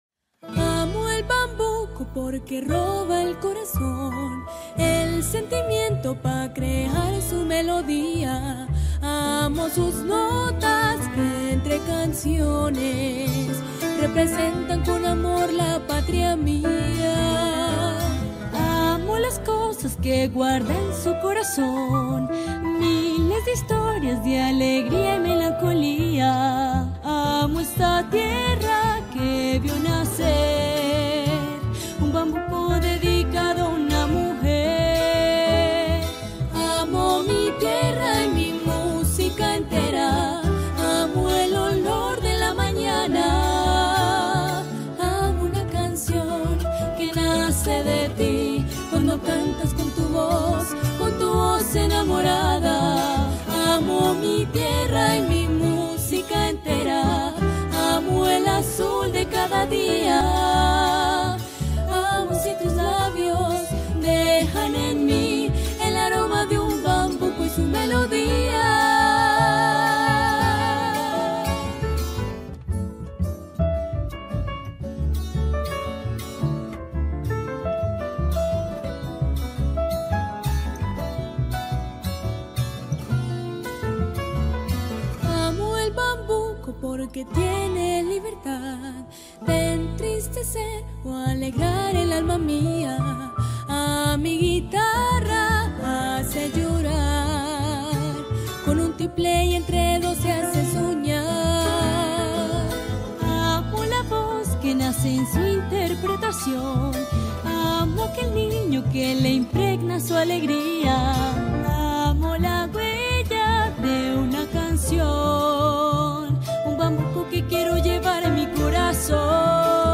Bambuco